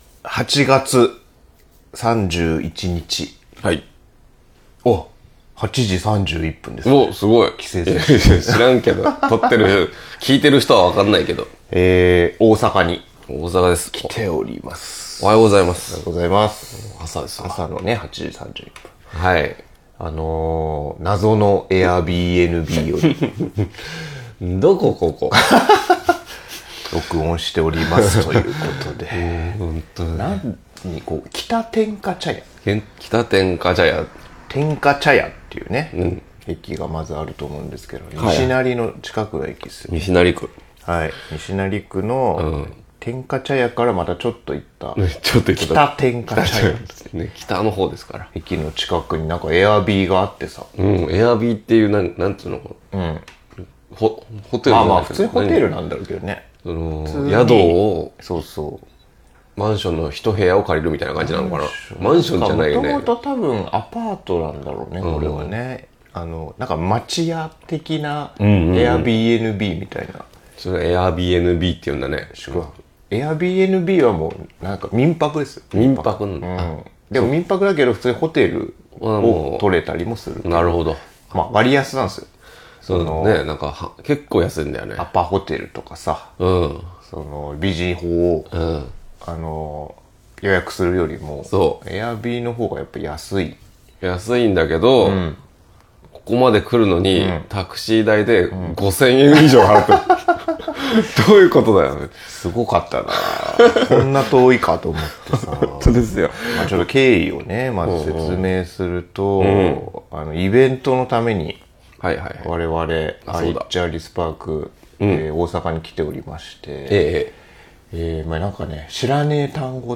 謎のAirbnbにて録音